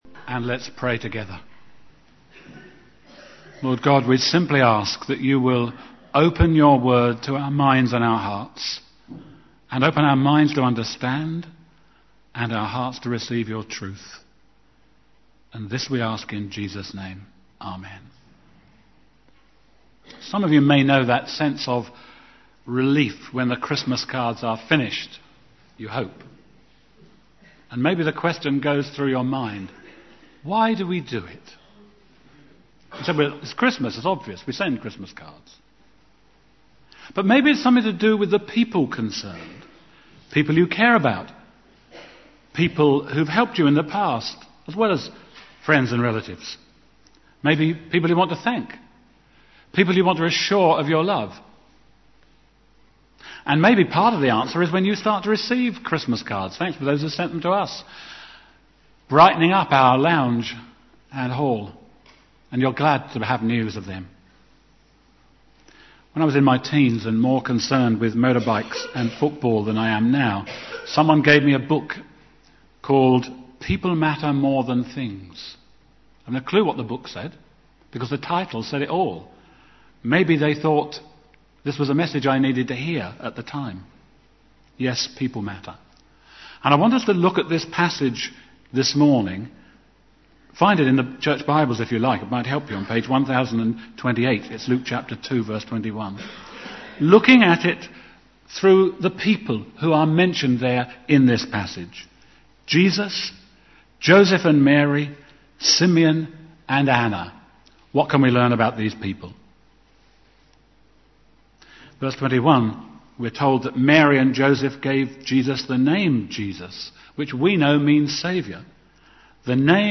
**************************** Early part of this service was not recorded due to a computer problem. ****************************
**************************** Preached on: 28 December, 2008 Service type: Sunday AM Bible Text: Luke 2:21-40